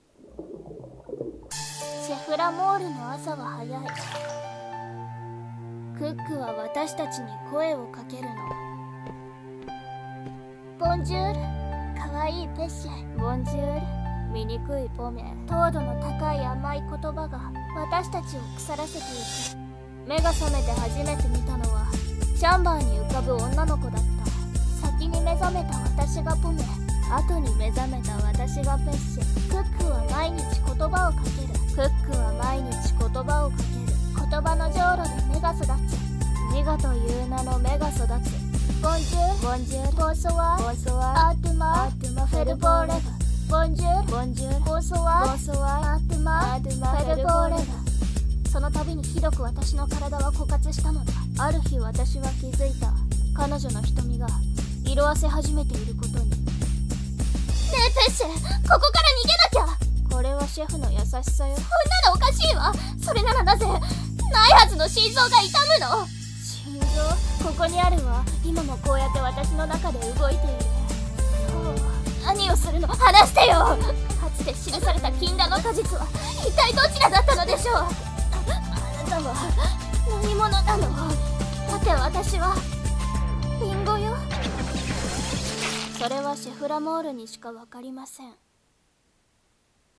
CM風声劇「ポミェとペッシェ